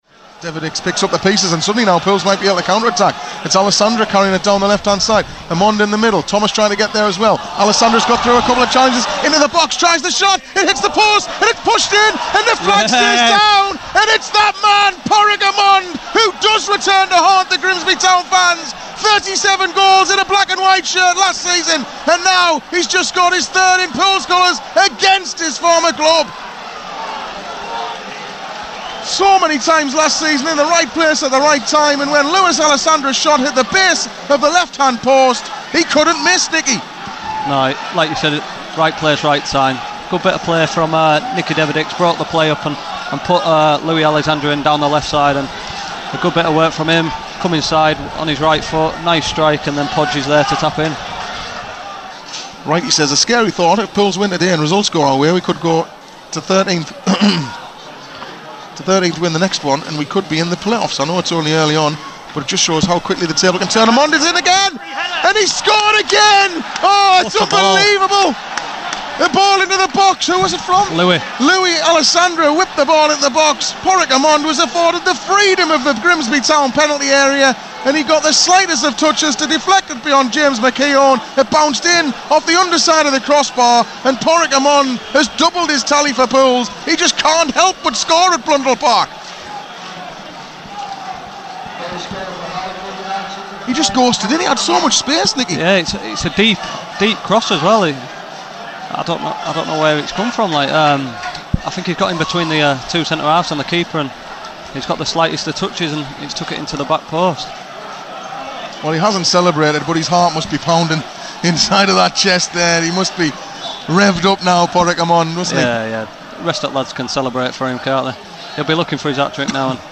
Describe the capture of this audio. Listen back to how the goals in our 3-0 win at Grimsby Town sounded as they went in live on Pools PlayerHD.